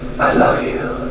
THE VOICE OF LANCE HENRIKSEN